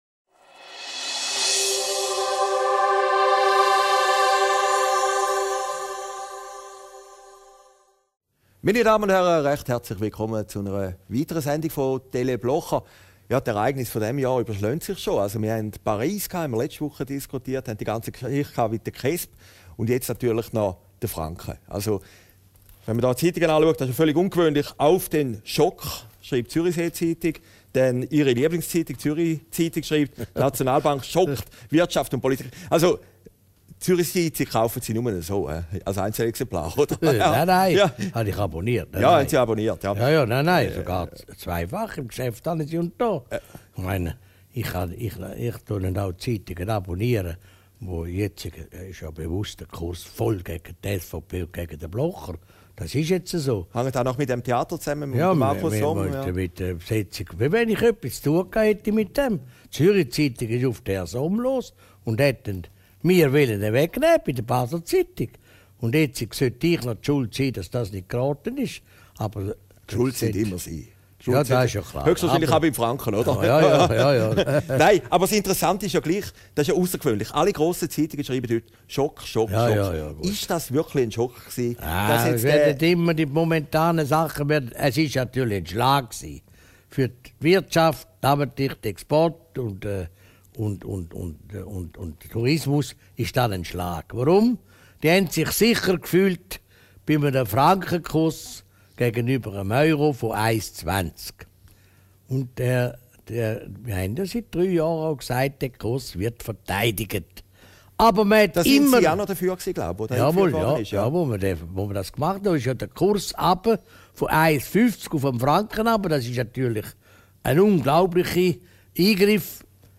Video downloaden MP3 downloaden Christoph Blocher über den Euroschock, die Sicherheit in der Schweiz und die Albisgüetli-Rede Aufgezeichnet in Herrliberg, 16.